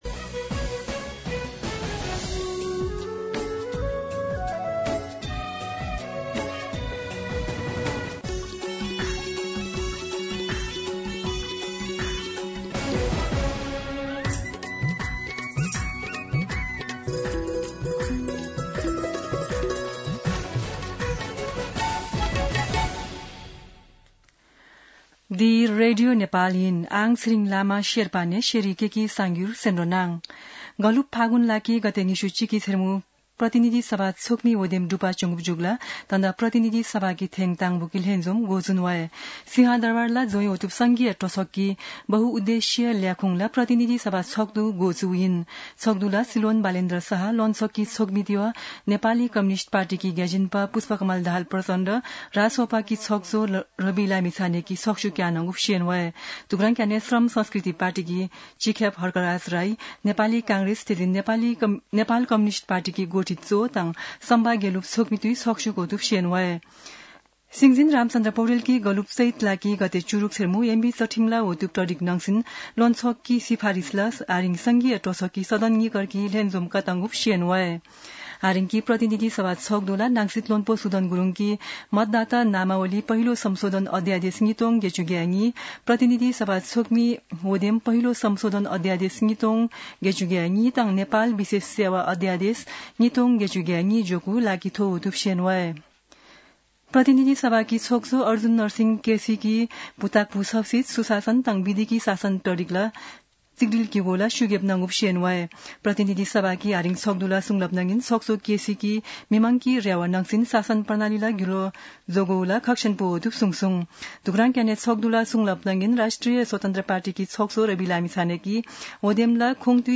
शेर्पा भाषाको समाचार : १९ चैत , २०८२
Sherpa-News-1.mp3